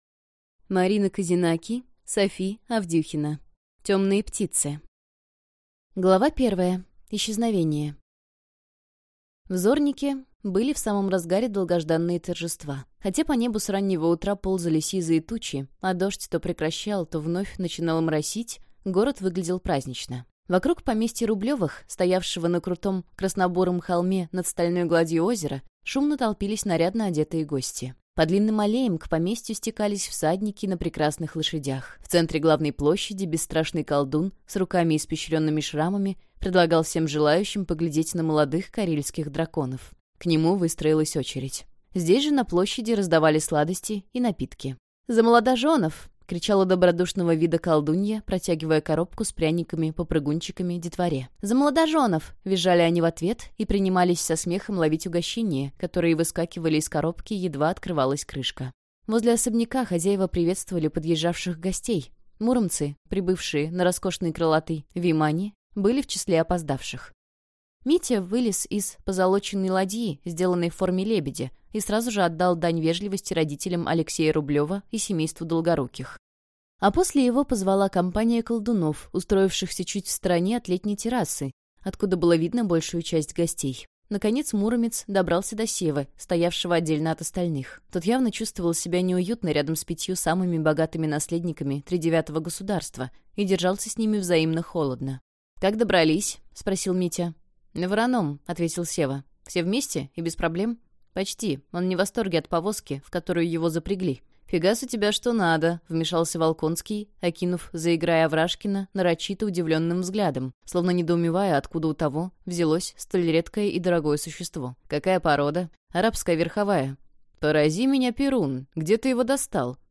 Аудиокнига Темные птицы | Библиотека аудиокниг